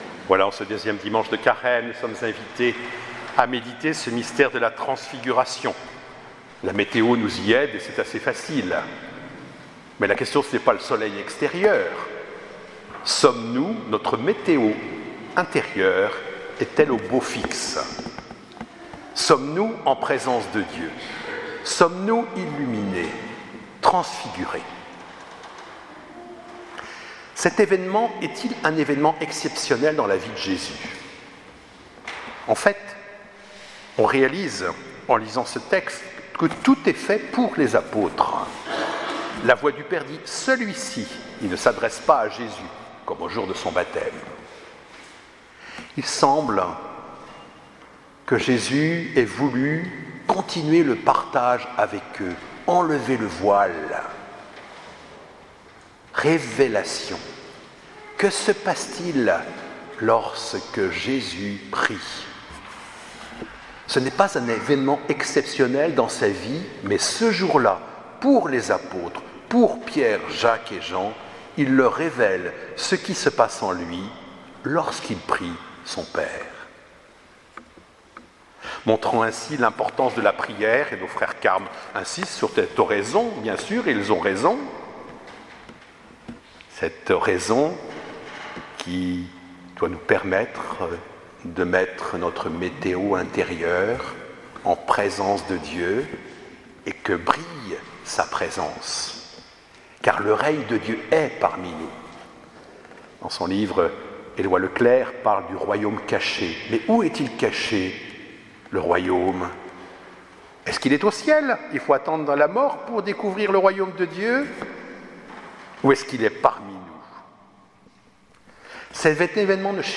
Homélie du 2ème dimanche de Carême 2018 | Les Amis du Broussey